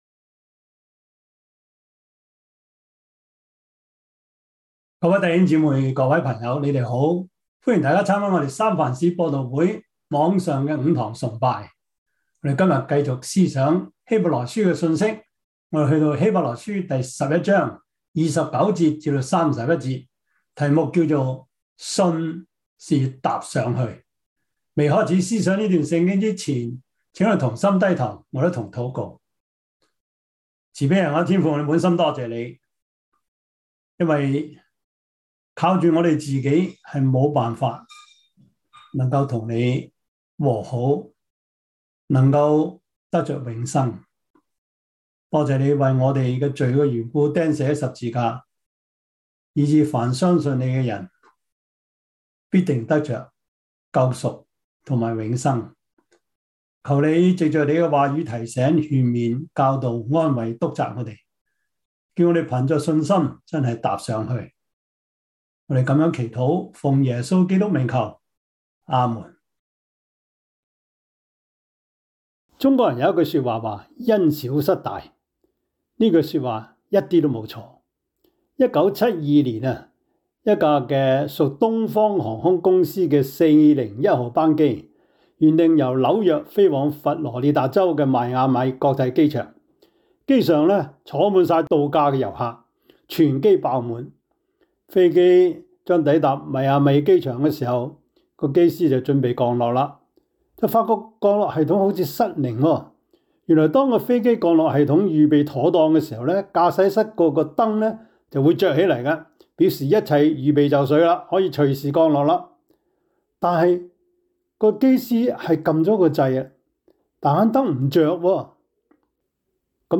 希伯來書 11:29-31 Service Type: 主日崇拜 希 伯 來 書 11:29-31 Chinese Union Version
Topics: 主日證道 « 主耶和華是我的力量 摩西五經 – 第十一課 »